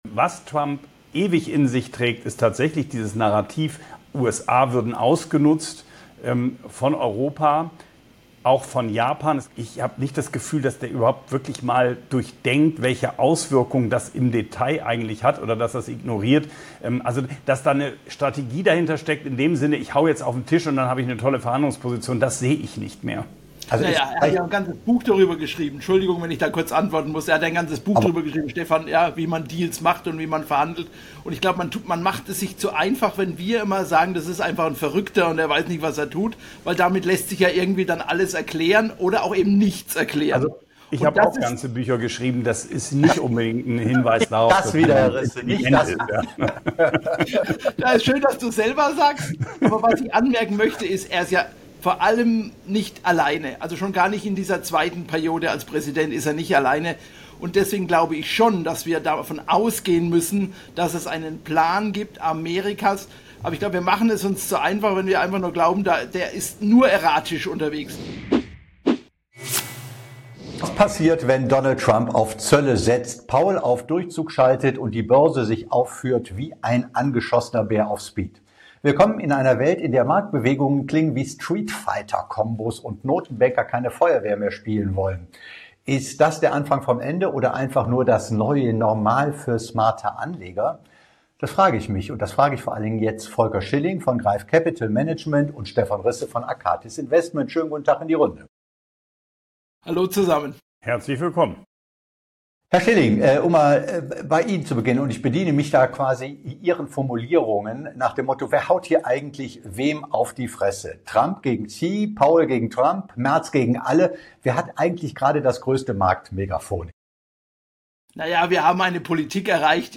Börsen unter Druck: Zwischen Rückzug und Rebound Ein lebendiges Gespräch für alle, die Wirtschaft, Politik und Börse verstehen wollen – und für alle, die sich fragen: Was jetzt?